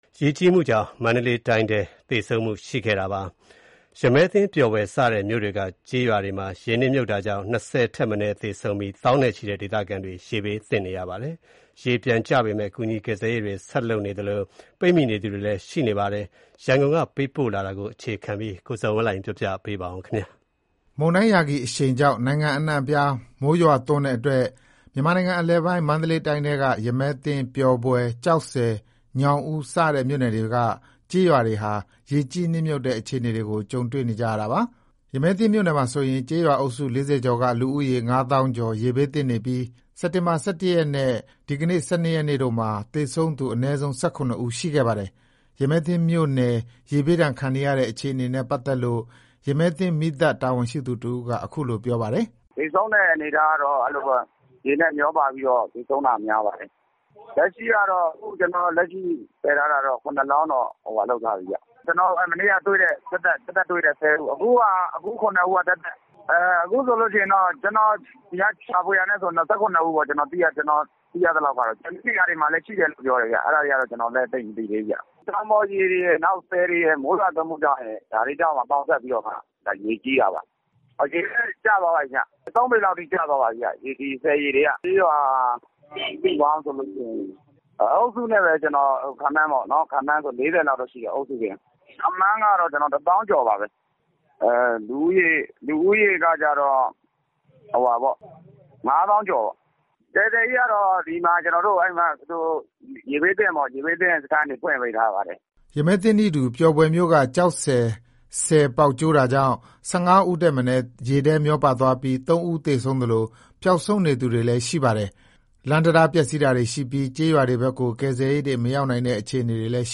မန္တလေးတိုင်း၊ ရမည်းသင်း ပျော်ဘွယ် စတဲ့မြို့တွေက ကျေးရွာတွေမှာ ရေနစ်မြှုပ်တာကြောင့် ၂၀ ဦးထက်မနည်းသေဆုံးပြီး သောင်းနဲ့ချီတဲ့ ဒေသခံတွေ ရေဘေးသင့်နေပါတယ်။ ရေပြန်ကျနေပေမယ့် ကူညီကယ်ဆယ်ရေးတွေ ဆက်လုပ်နေသလို ပိတ်မိနေသူတွေလည်း ရှိနေပါတယ်။ ဒီအကြောင်း ရန်ကုန်ကပေးပို့တဲ့သတင်းကို တင်ပြပါမယ်။